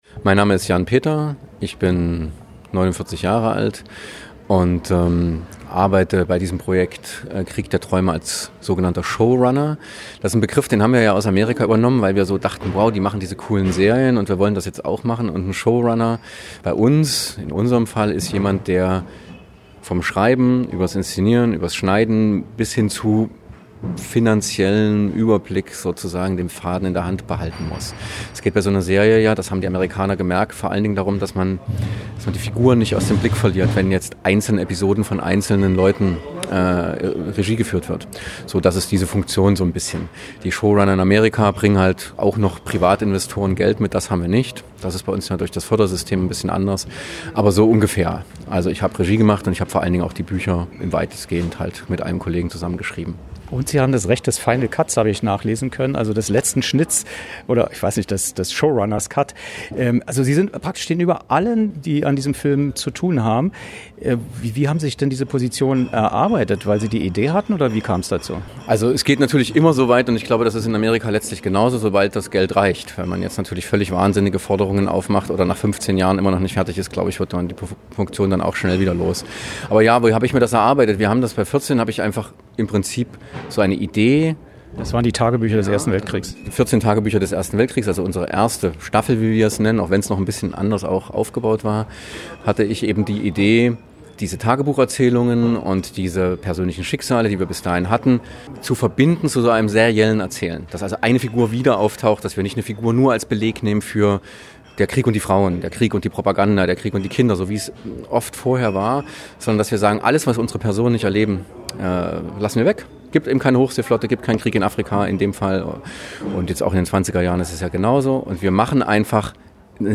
Making-of-Interview
Berlin, Astor Filmlounge